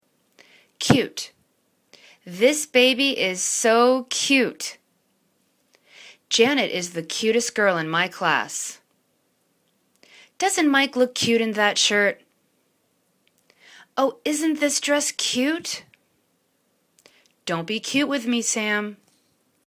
cute     /kyu:t/    n